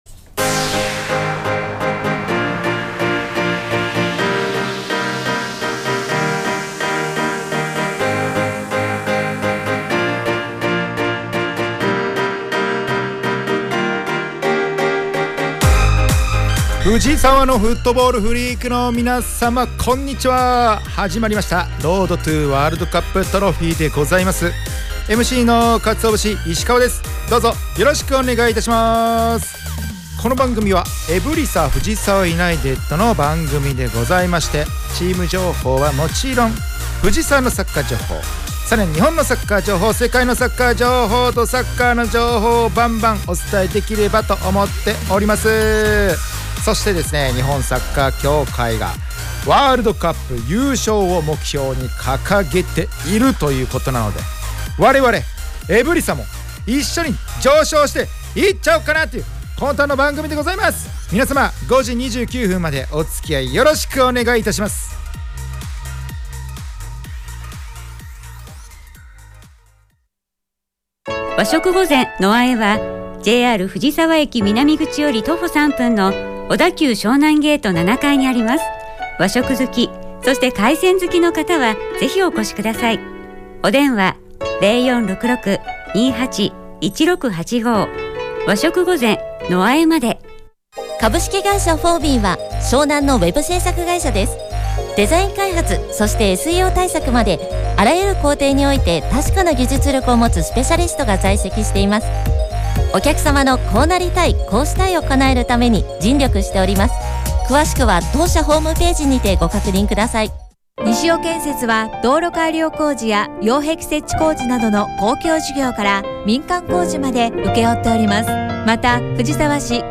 【ラジオ】Road to WC Trophy 2期 第84回放送 – エブリサ藤沢ユナイテッド
エブリサ藤沢ユナイテッドが提供する藤沢サッカー専門ラジオ番組『Road to WC Trophy』の第2期の第84回放送が11月7日(金)17時に行われました☆